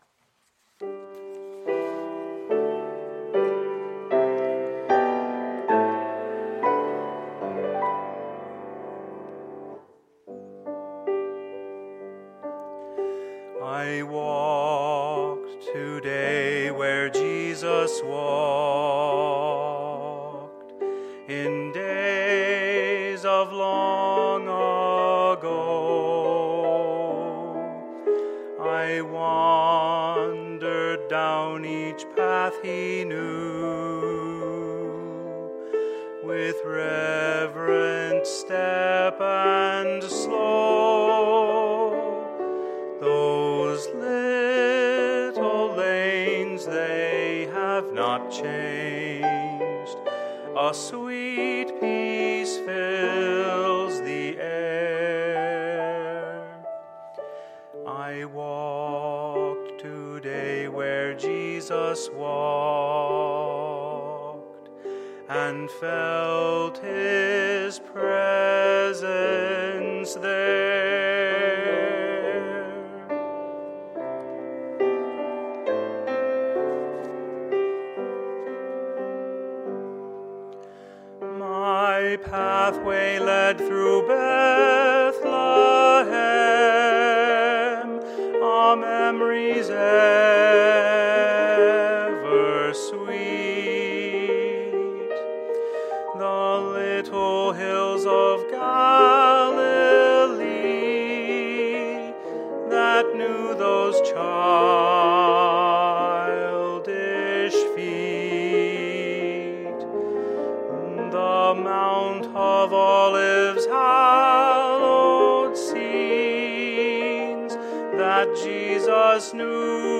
Friday, March 25, 2016 – Crucifixion Service